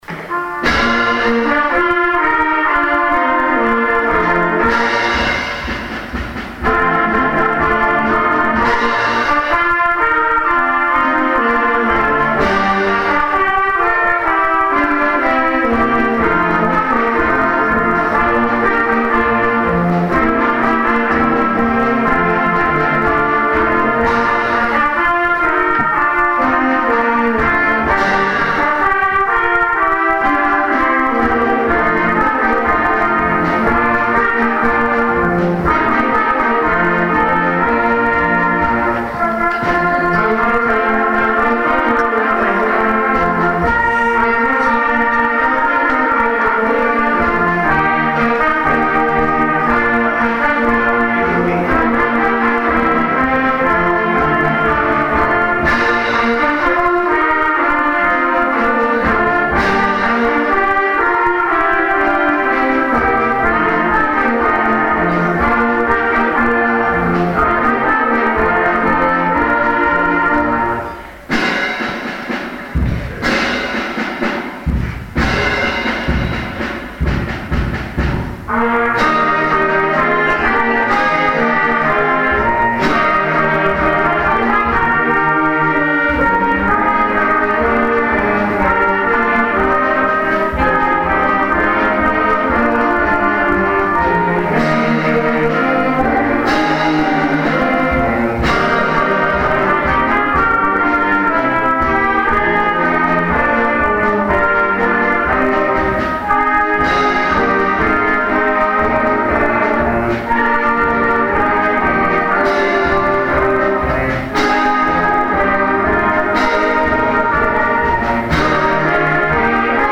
Tonight we went to the new Arts Center to see the Riverside Senior Band play, along with another group.
Here are two clips of the concert, Riverside Senior Band playing